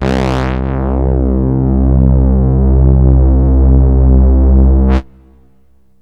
SYNTH LEADS-2 0006.wav